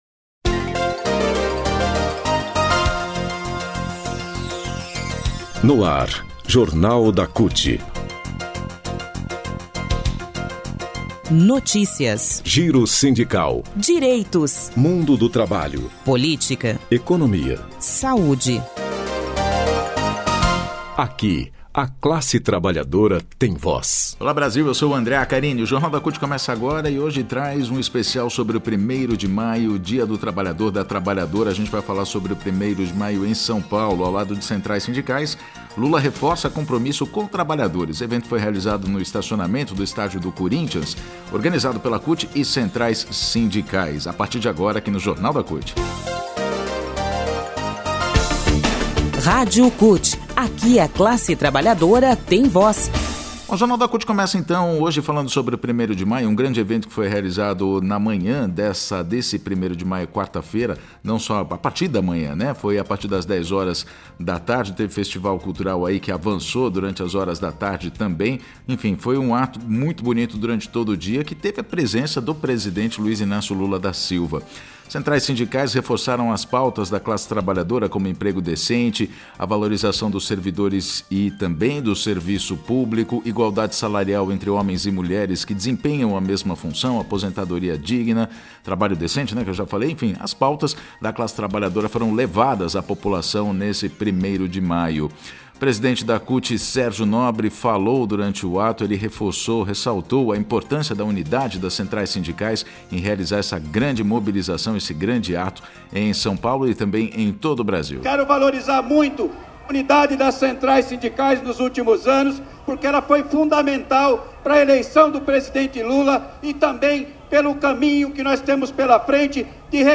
Evento realizado no estacionamento do estádio do Corinthians organizado pela CUT e centrais sindicais